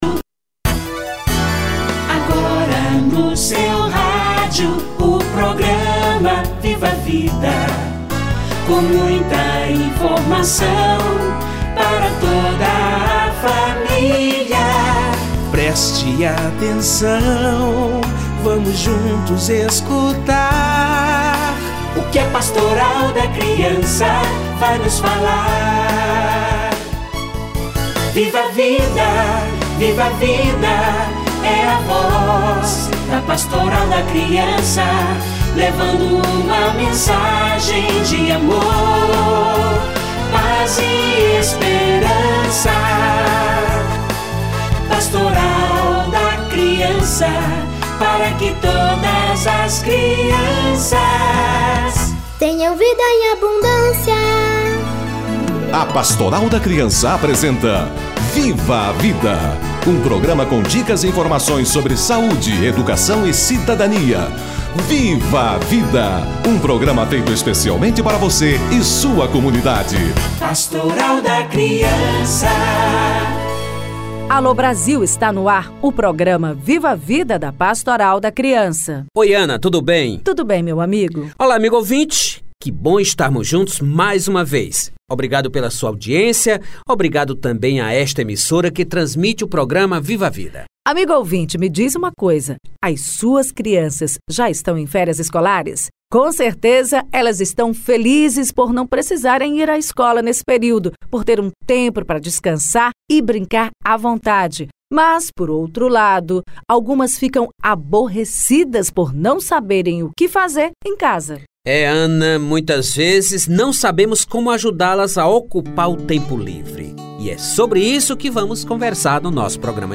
Férias escolares - Entrevista